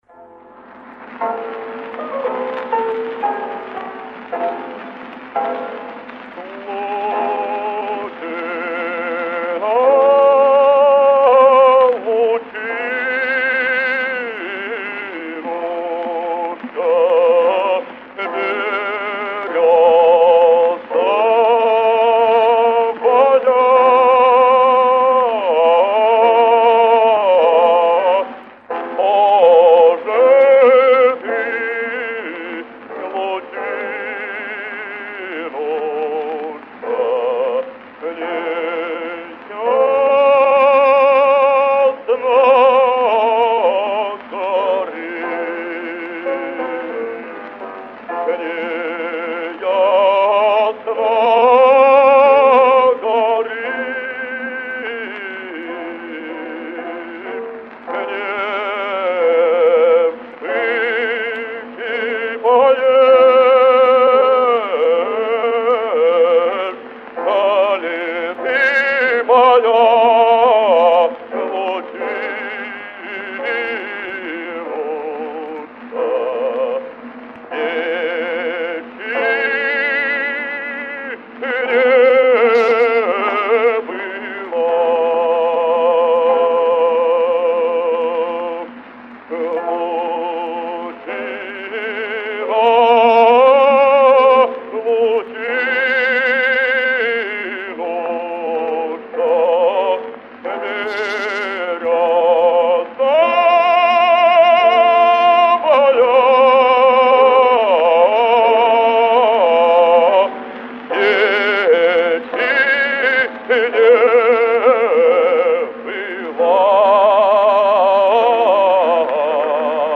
Петров обладал гибким выразительным голосом широкого диапазона, сочетавшим мягкость и красоту звучания с мощью и редкой для баса колоратурной техникой.
В.Петров - Народный артист Республики (1933).